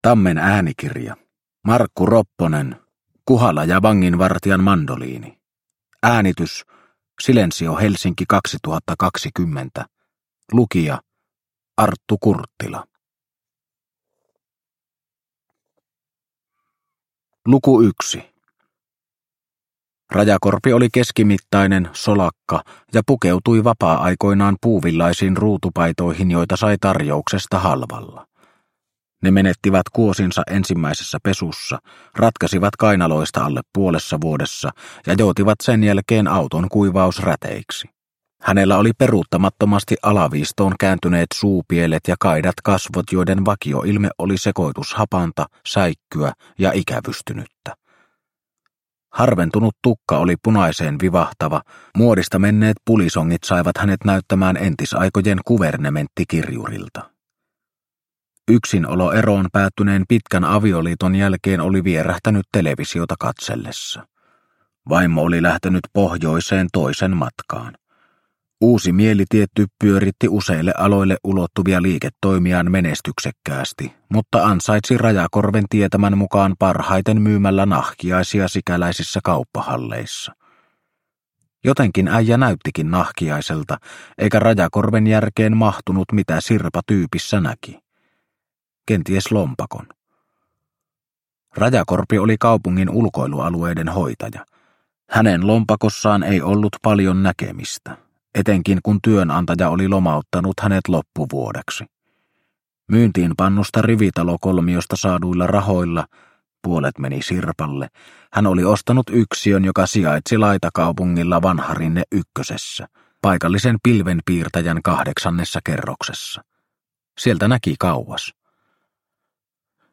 Kuhala ja vanginvartijan mandoliini – Ljudbok – Laddas ner